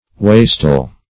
wastel - definition of wastel - synonyms, pronunciation, spelling from Free Dictionary
Wastel \Was"tel\, n. [OF. wastel, gastel, F. g[^a]teau, LL.